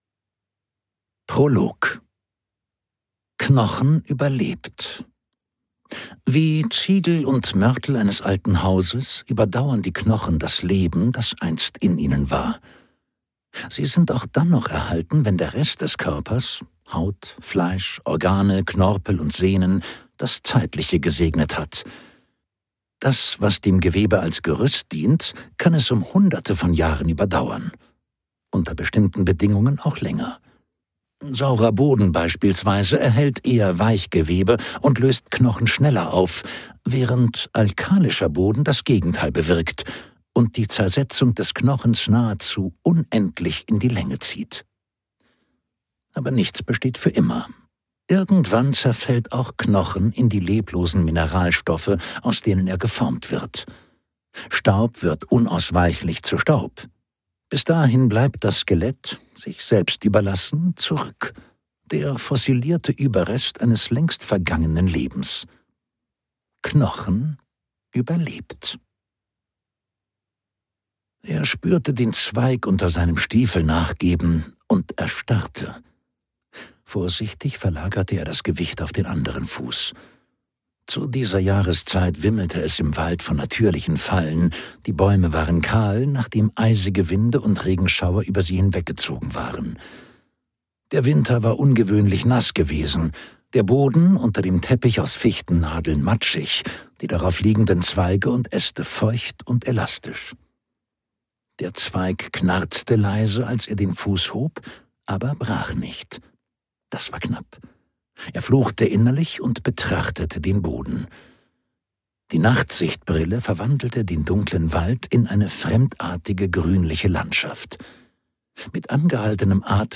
Knochenkälte - Simon Beckett | argon hörbuch
Gekürzt Autorisierte, d.h. von Autor:innen und / oder Verlagen freigegebene, bearbeitete Fassung.